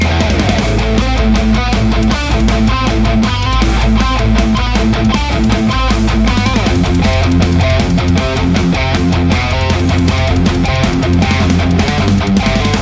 Metal Riff Mix
RAW AUDIO CLIPS ONLY, NO POST-PROCESSING EFFECTS
Hi-Gain